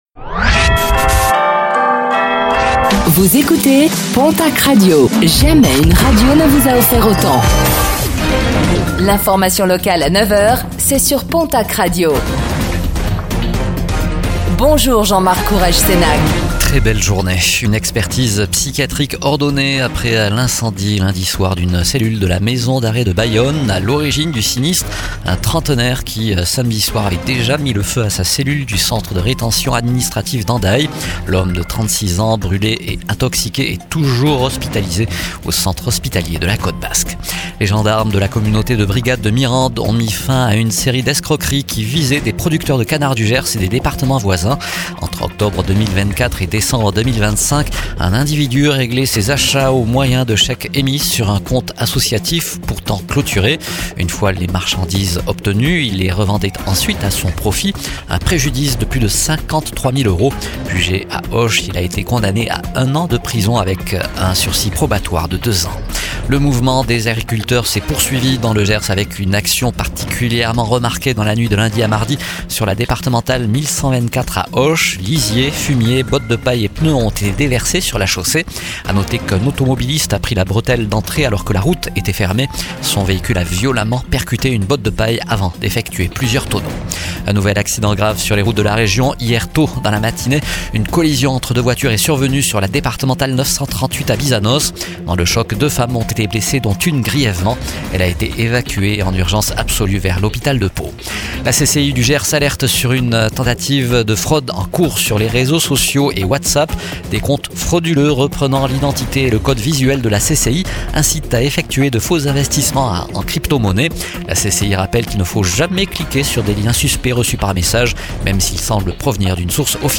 Réécoutez le flash d'information locale de ce mercredi 24 décembre 2025